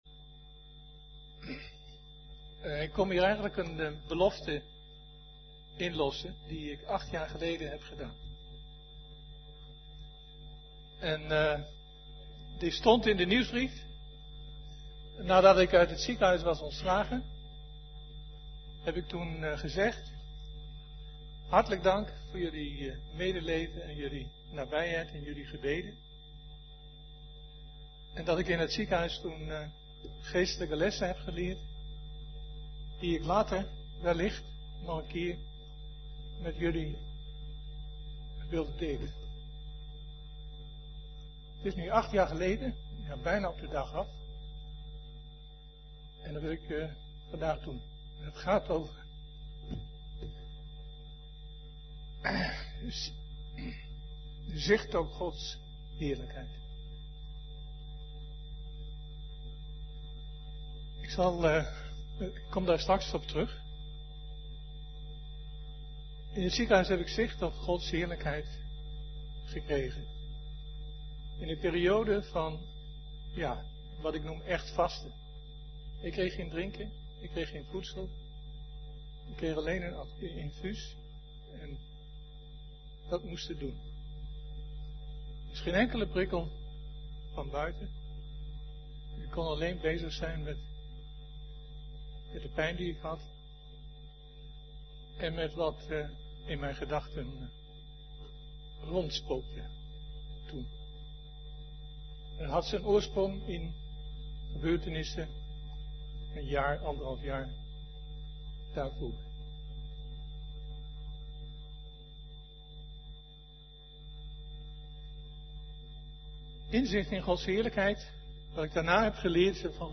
Toespraak van 28 april: Met Johannes op weg met Jezus en Zijn Heerlijkheid zien - De Bron Eindhoven